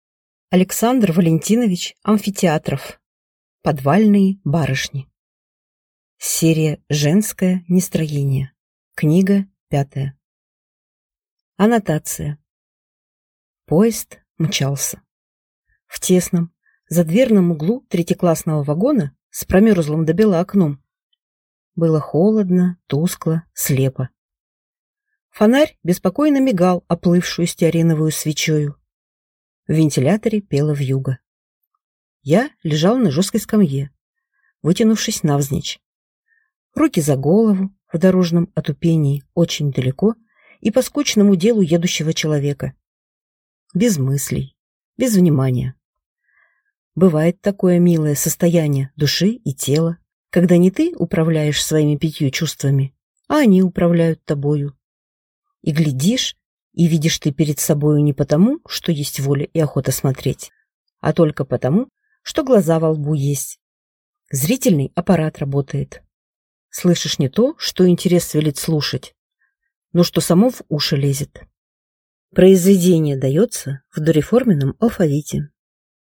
Аудиокнига Подвальные барышни | Библиотека аудиокниг